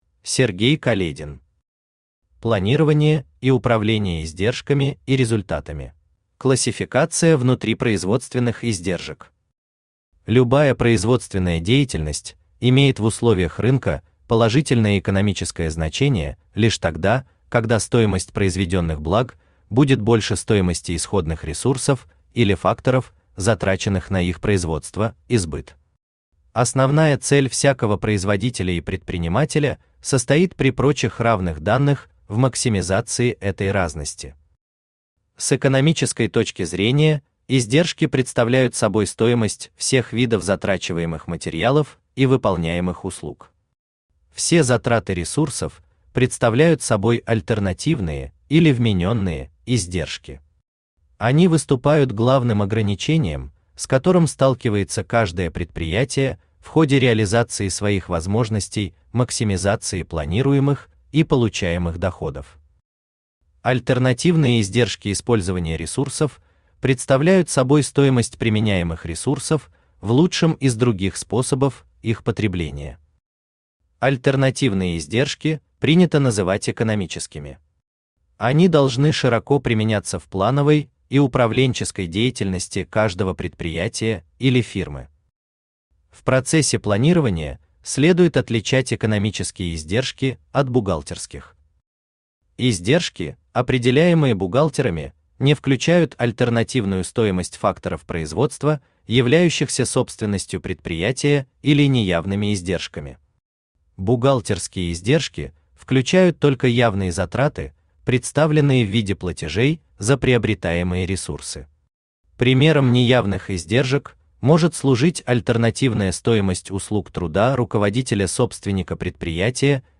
Aудиокнига Планирование и управление издержками и результатами Автор Сергей Каледин Читает аудиокнигу Авточтец ЛитРес.